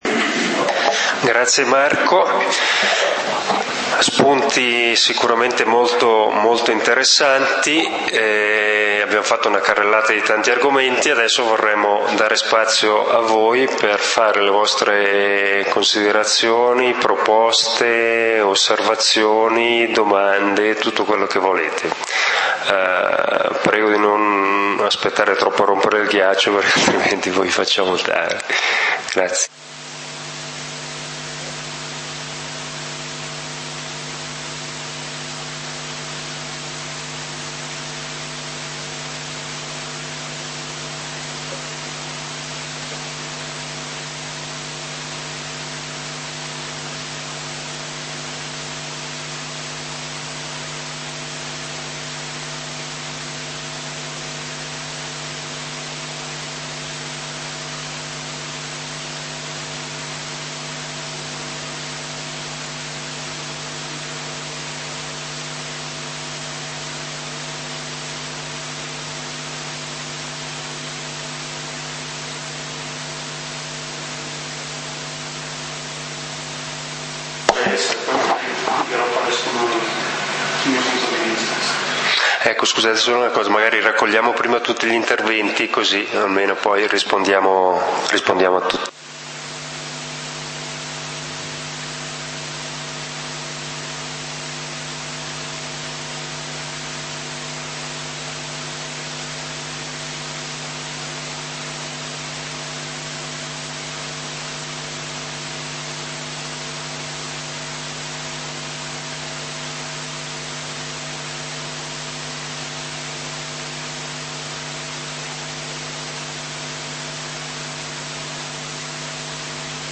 Turismo: informazione, confronto ed approfondimento Assembrela pubblica del comunale di Valdidentro del 14 Ottobre 2013
Assemblea pubblica del 14 Ottobre 2013 - Turismo : informazione, confronto ed approfondimento torna alla lista dei punti Punto 6: Dibattito; Scarica i file di questo punto: file video in formato flv file audio in formato mp3